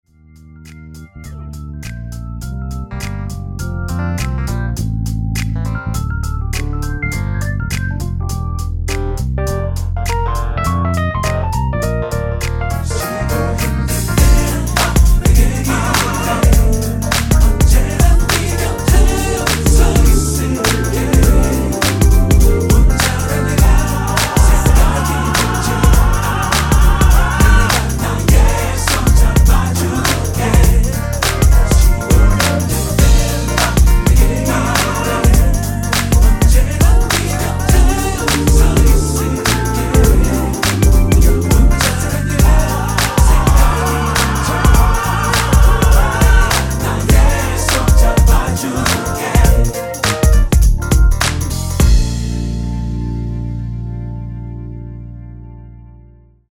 MR은 2번만 하고 노래 하기 편하게 엔딩을 만들었습니다.(본문의 가사와 미리듣기 확인)
원키에서(-2)내린 코러스 포함된 MR입니다.
Bb
앞부분30초, 뒷부분30초씩 편집해서 올려 드리고 있습니다.
중간에 음이 끈어지고 다시 나오는 이유는